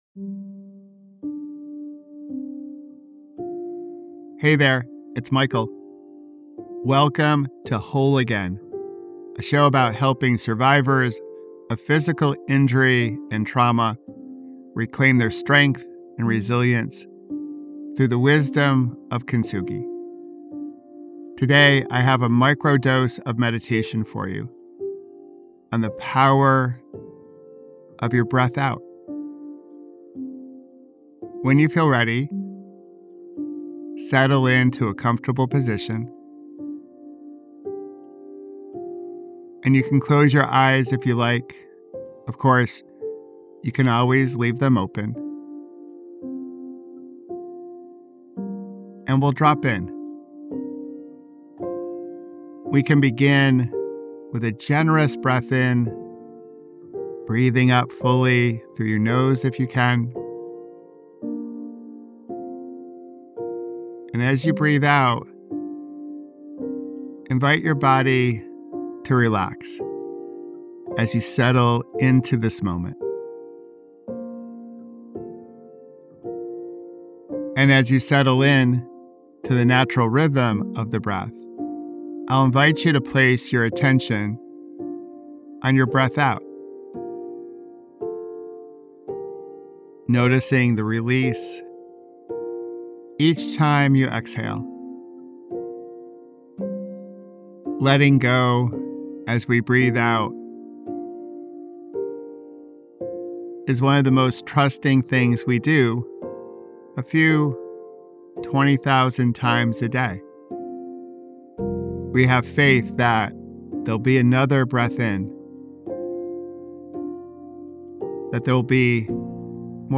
• Experience a guided moment of mindfulness to carry with you throughout your day.